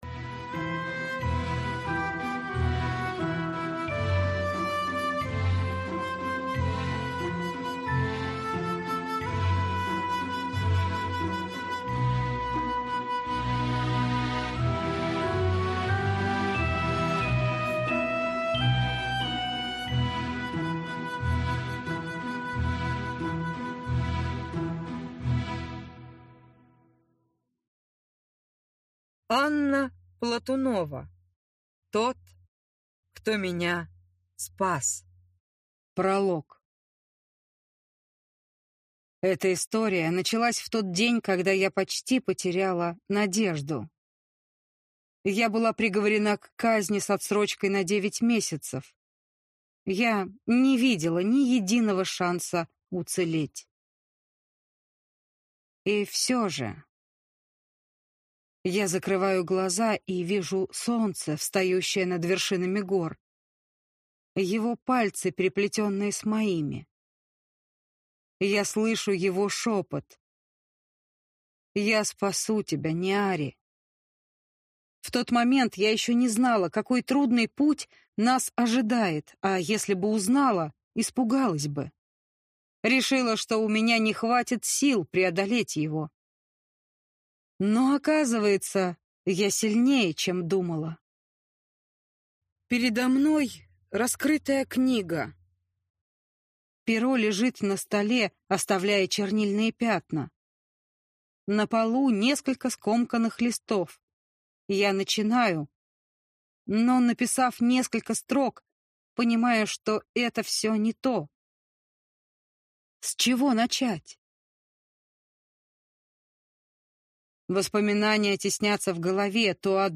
Аудиокнига Тот, кто меня спас | Библиотека аудиокниг